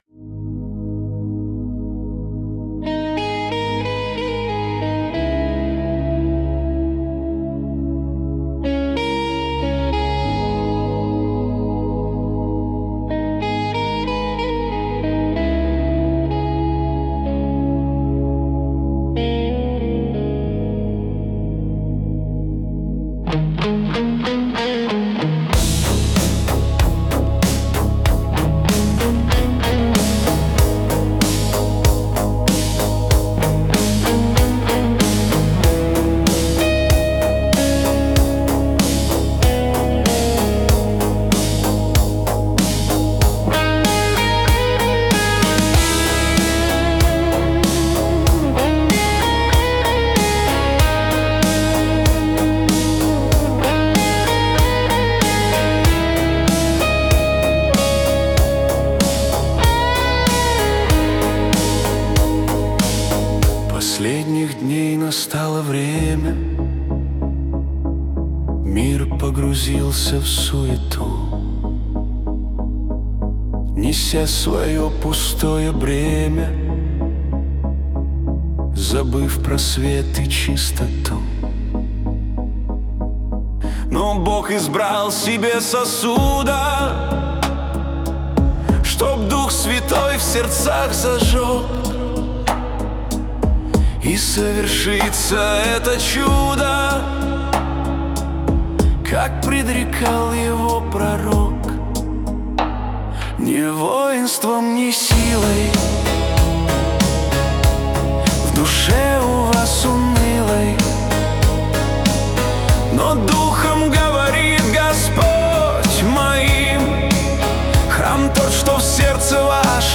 песня ai
280 просмотров 987 прослушиваний 184 скачивания BPM: 95